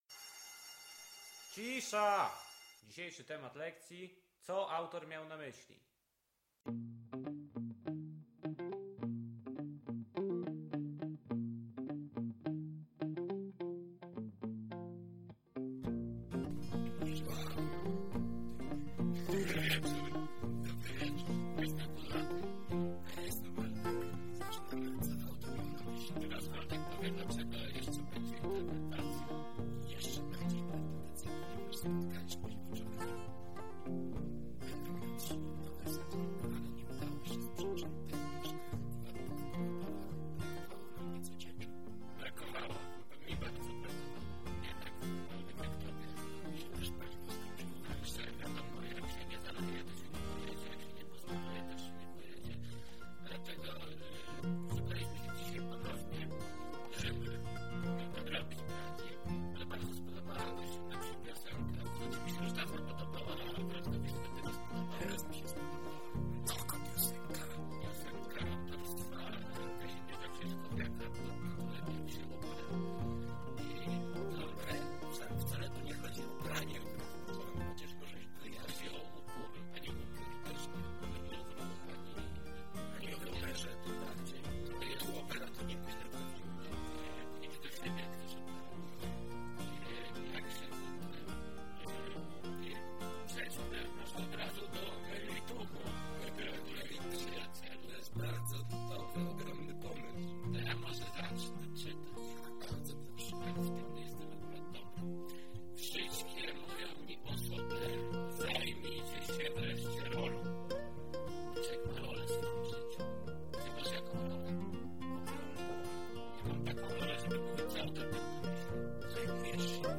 Wesoły, żywy odcinek a w nim bardzo polski akcent upierania się na cel. Krótki, zwiewny i przyjemny.